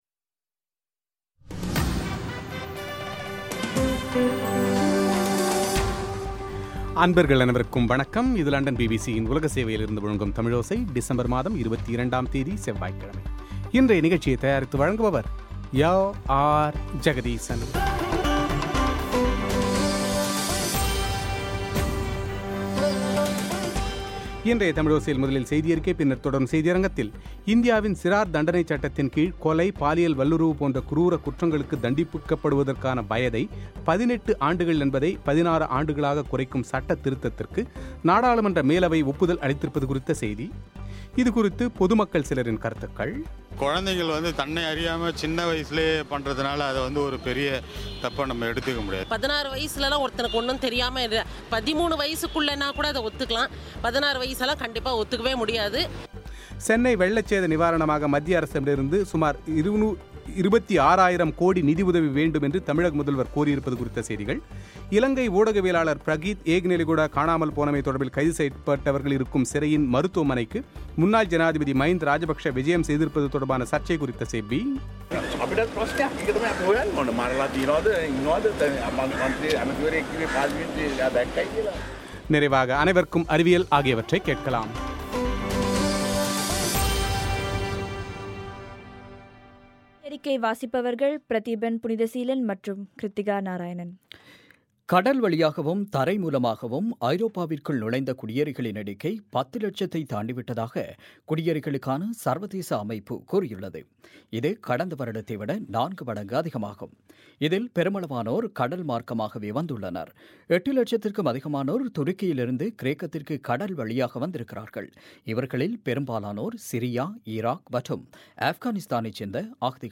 இதுகுறித்த பொதுமக்கள் சிலரின் கருத்துக்கள்;